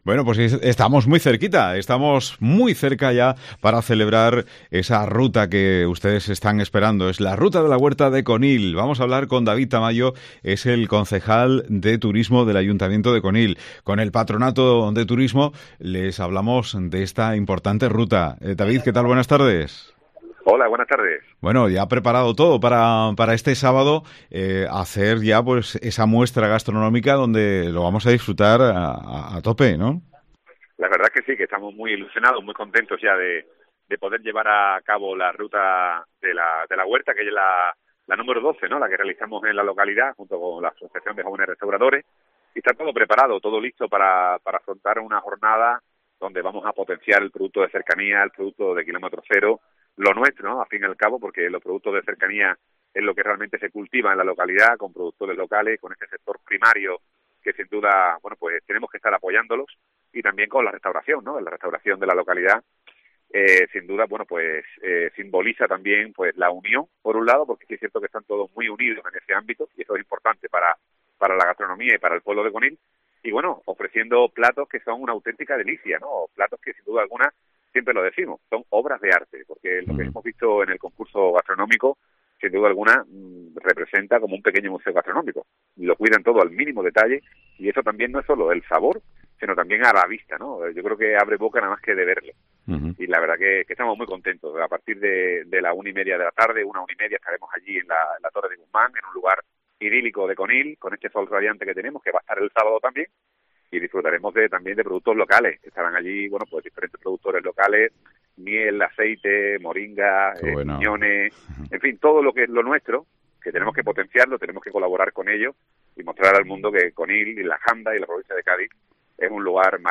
David Tamayo, Deleg de Turismo del Ayto de Conil - Inauguración XII Ruta de la Huerta de Conil